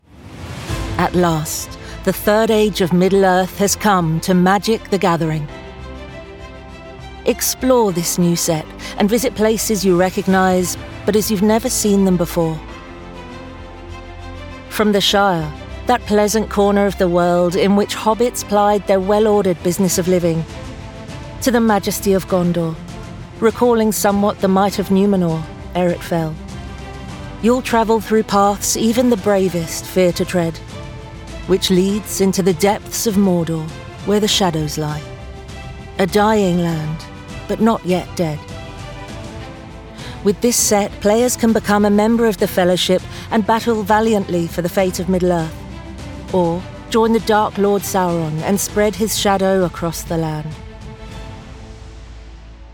Anglais (britannique)
Bandes annonces